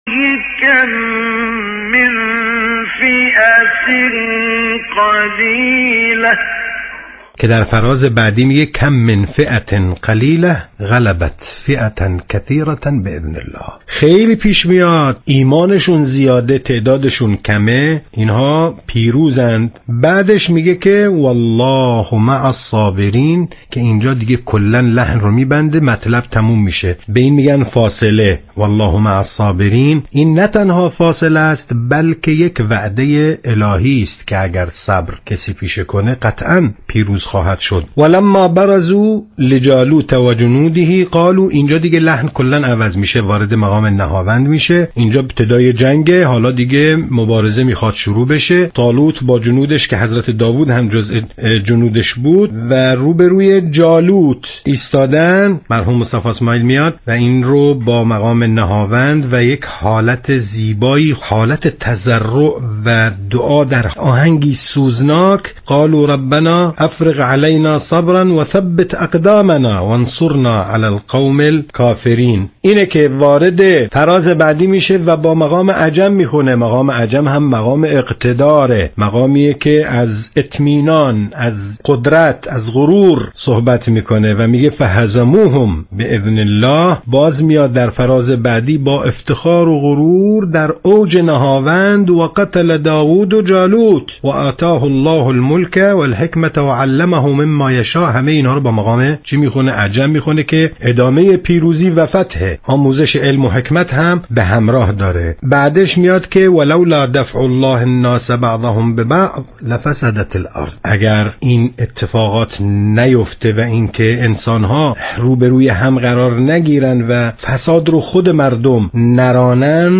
یادآوری می‌شود، این تحلیل در برنامه «اکسیر» از شبکه رادیویی قرآن پخش شد.